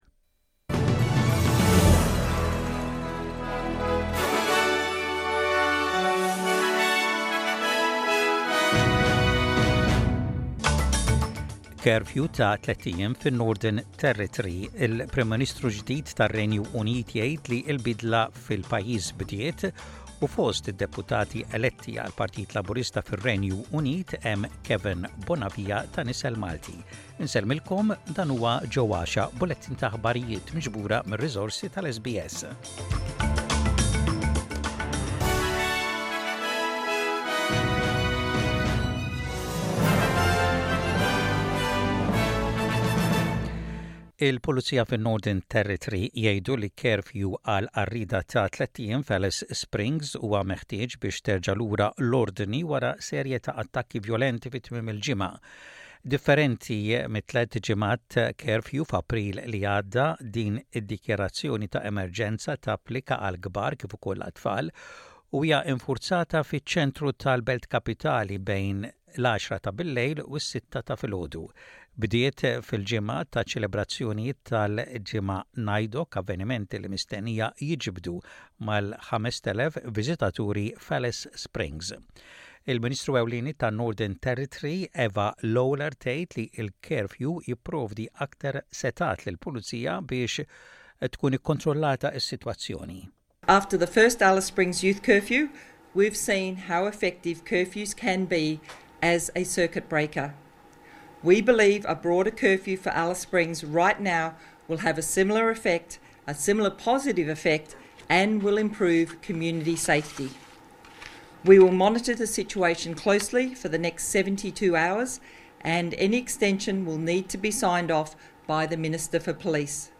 SBS Radio | Aħbarijiet bil-Malti: 09.07.24